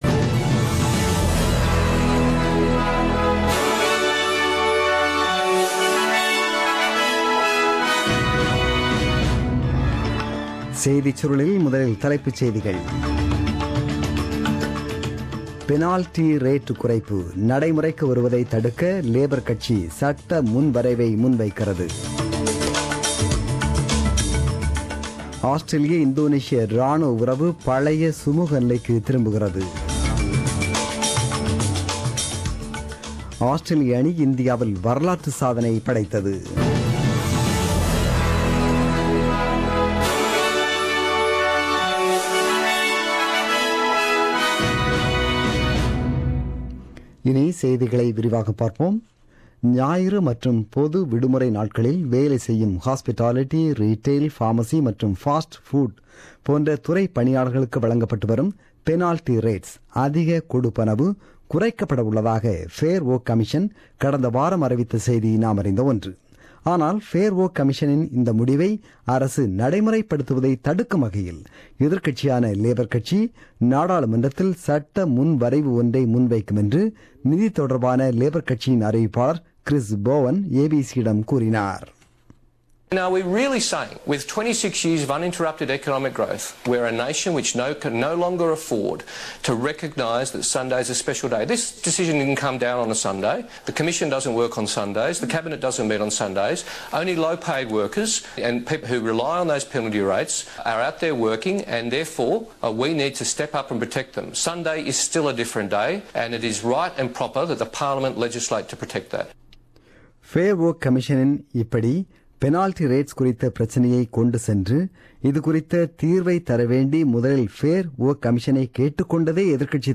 The news bulletin broadcasted on 26 February 2017 at 8pm.